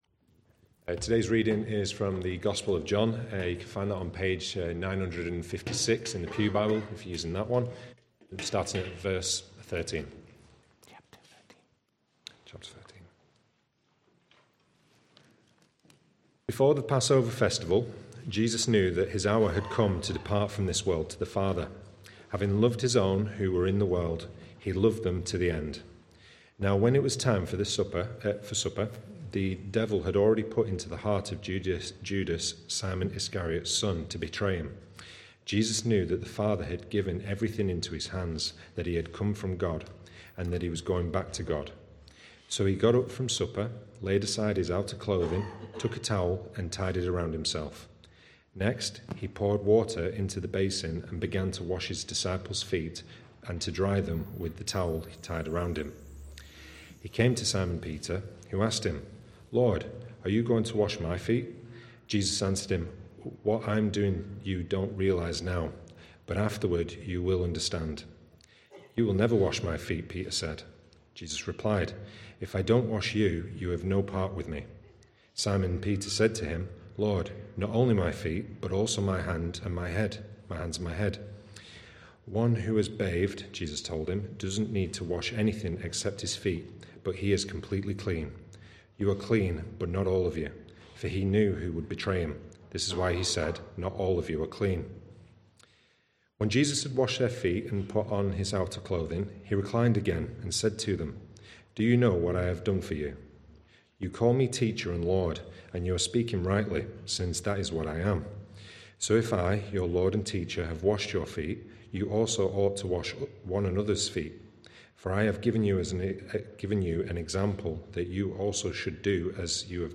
From Series: "Other Sermons"